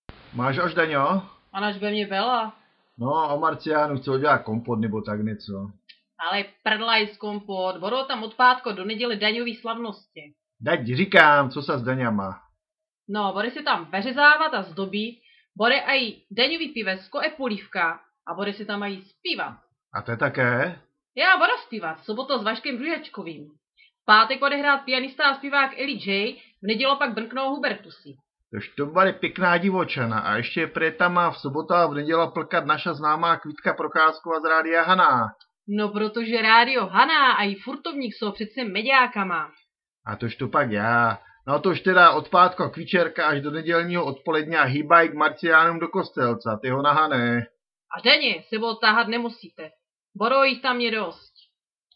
V audiô si mužete pôstit aji našô hlasovó pozvánkô, ať vite, co vás bôde ô Marciánu v Kostelcô čekat.